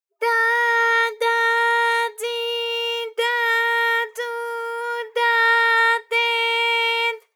ALYS-DB-001-JPN - First Japanese UTAU vocal library of ALYS.
da_da_di_da_du_da_de_d.wav